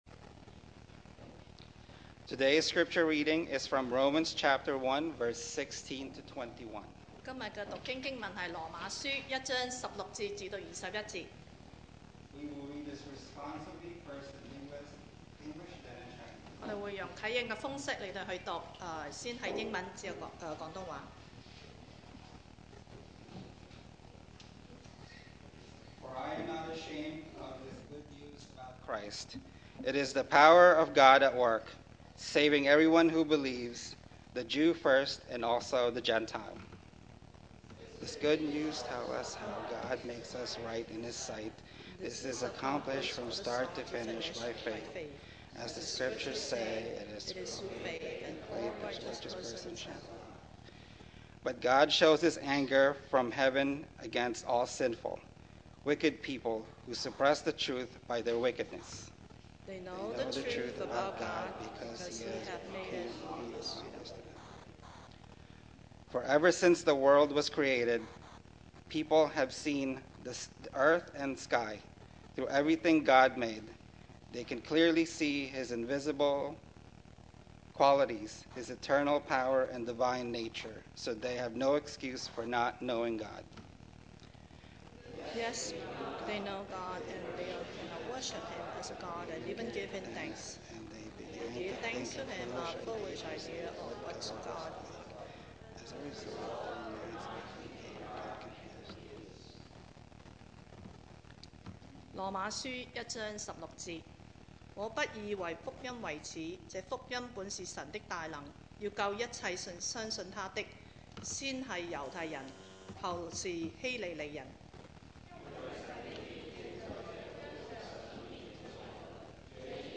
2024 sermon audios
Service Type: Sunday Morning